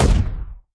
etfx_explosion_grenade.wav